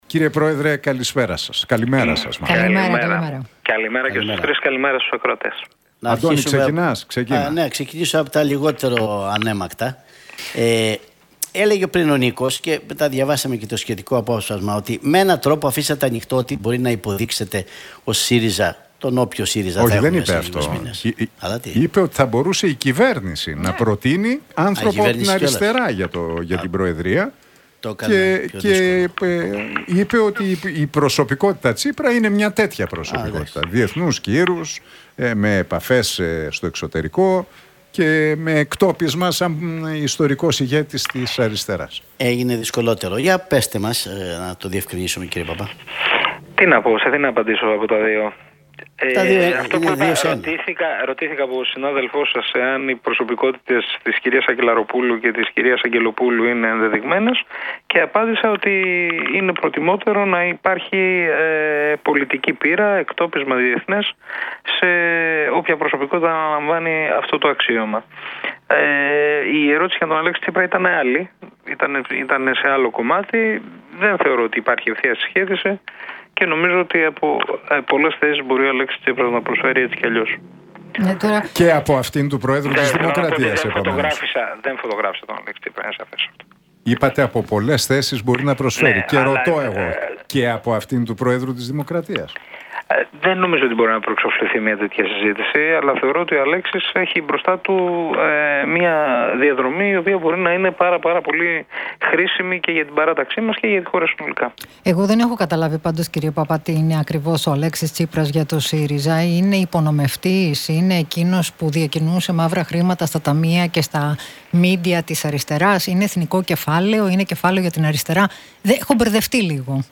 Παππάς στον Realfm 97,8: Δεν είναι στη σκέψη μου να είμαι υποψήφιος για την προεδρία του ΣΥΡΙΖΑ - Τι είπε για τον Αλέξη Τσίπρα